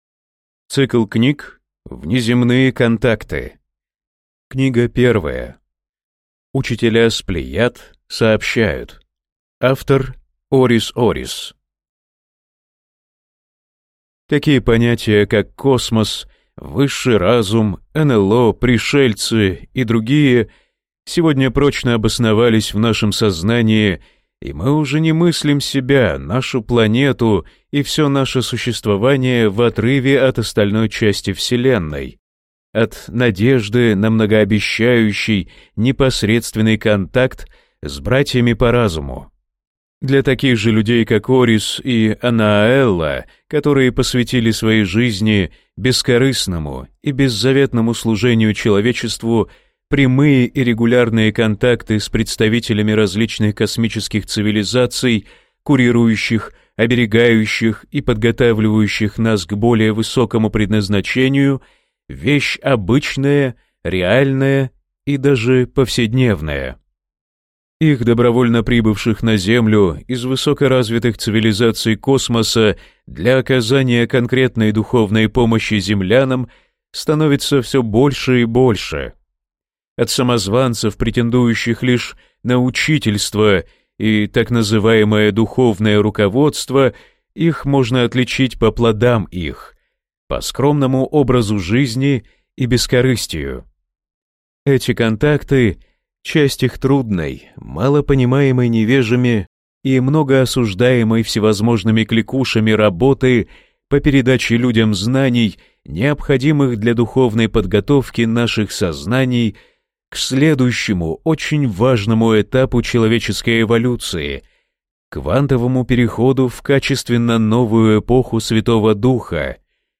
Аудиокнига Учителя с Плеяд сообщают | Библиотека аудиокниг